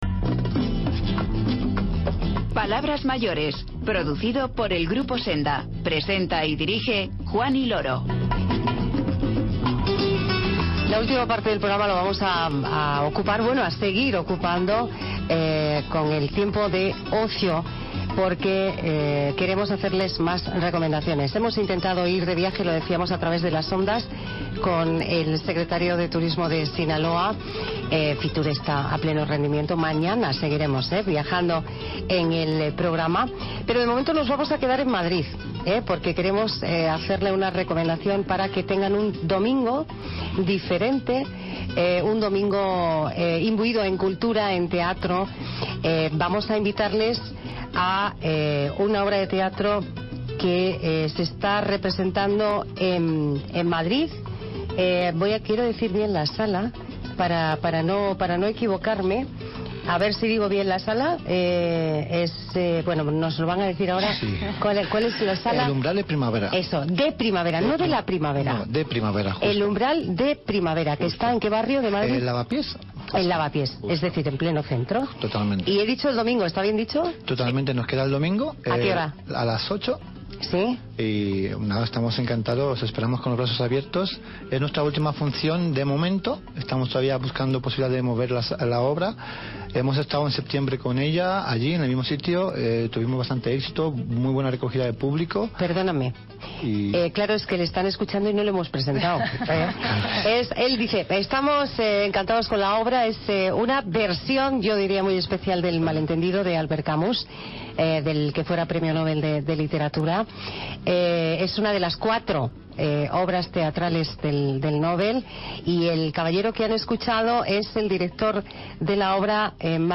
El secretario de Turismo deSinaloa, Óscar Pérez Barros, nos cuenta cuáles son los atractivos turísticos de este estado mexicano.